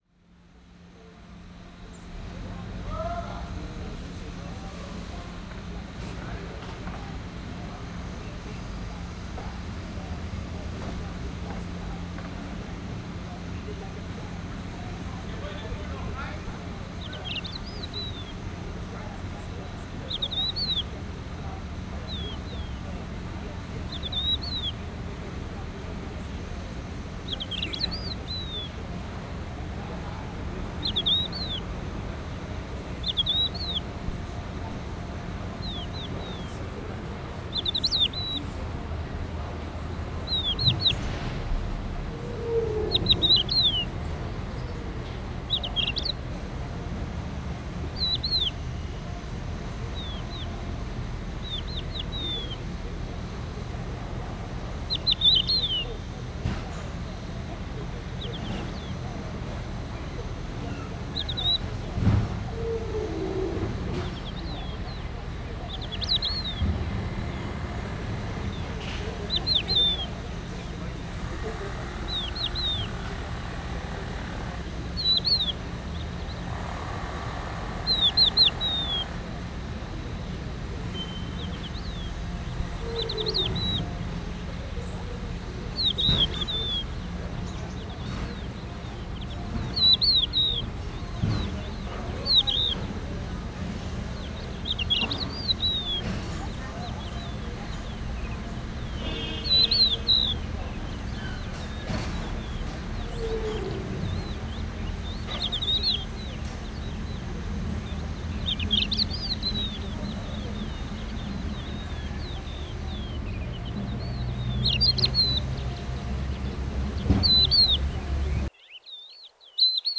Urban waste land
PFR05312, PFR08178, Crested Lark, song; construction site – reconstruction
PFRTEMP, Pied Wheatear, 2cy male, plastic song
Kathewitz, Germany